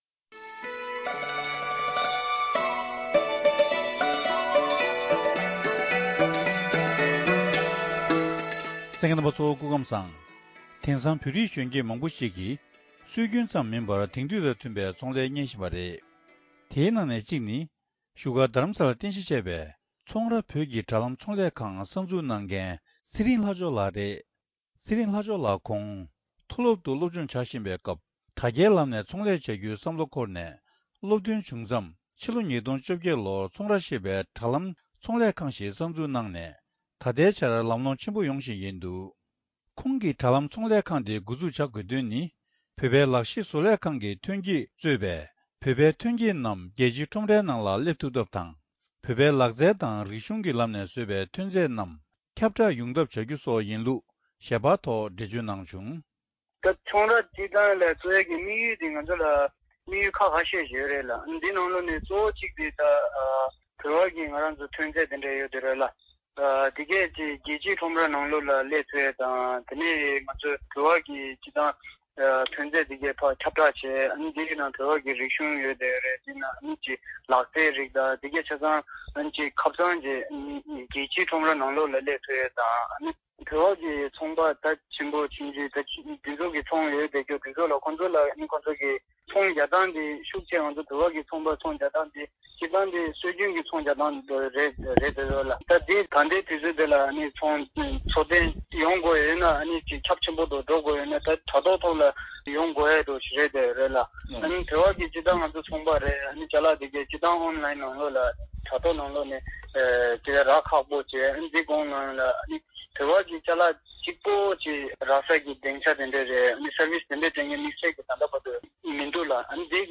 དྲ་ཐོག་ཚོང་ལས་གཉེར་རྒྱུར་ཚོང་གི་འགྲོ་རྒྱུགས་དང་ཁེ་སང་དེ་བཞིན་གདོང་ལེན་བྱ་དགོས་པ་སོགས་ཀྱི་སྐོར་འབྲེལ་ཡོད་ལ་བཀའ་འདྲི་ཞུས་པ།